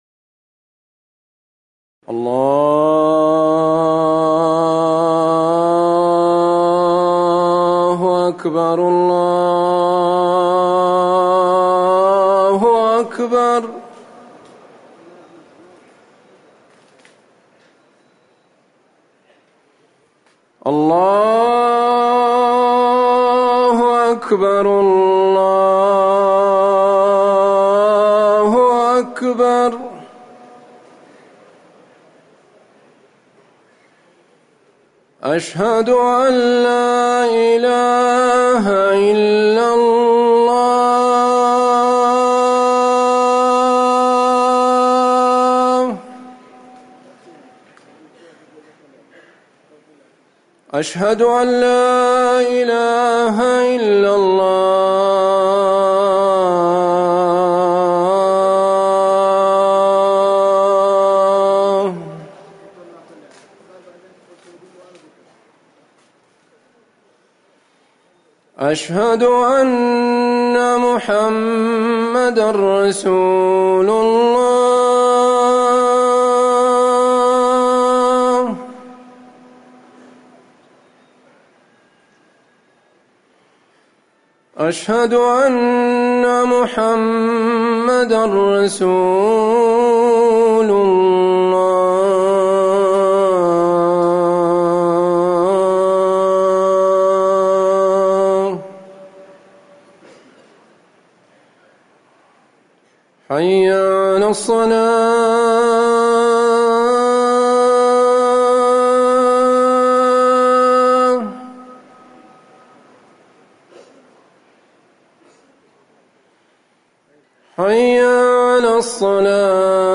أذان المغرب
تاريخ النشر ١٣ محرم ١٤٤١ هـ المكان: المسجد النبوي الشيخ